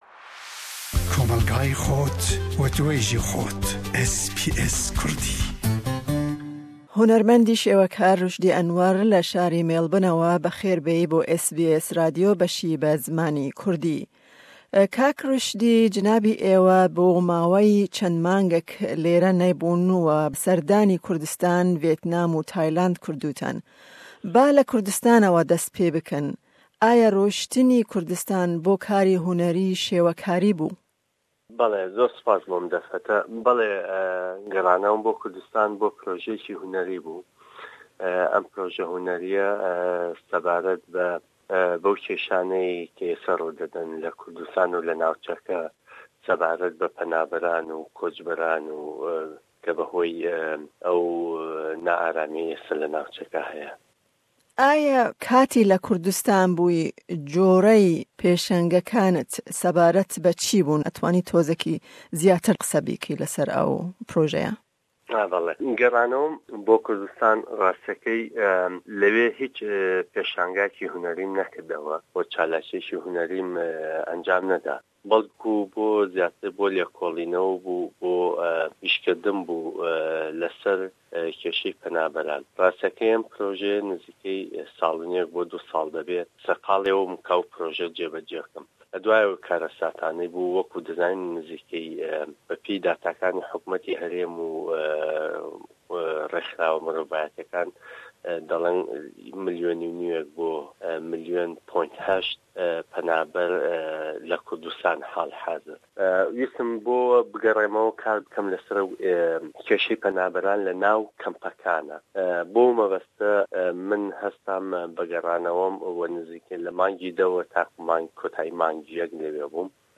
hevpeyvînek